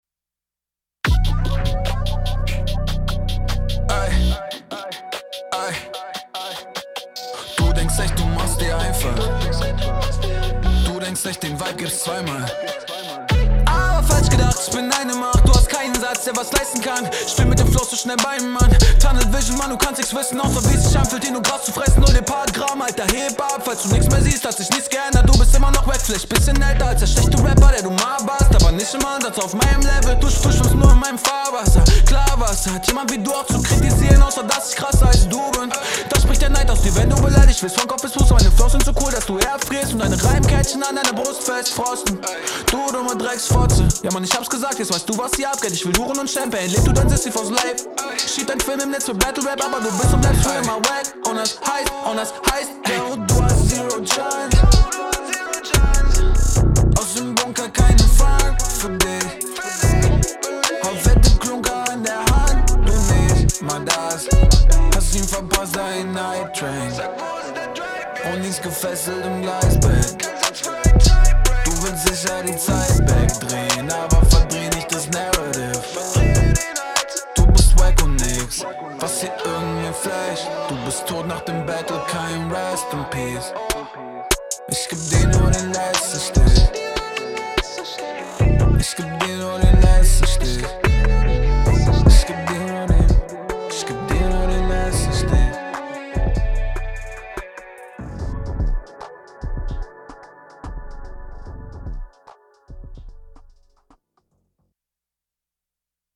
^^ komplett Autotuneschiene hier. Flowtechnisch hast du dich hier aufjedenfall gesteigert.
Okay, trap-autotune Runde, gar nicht mein Style, in meinen Augen auch vollkommen ungeeignet für Battlerap.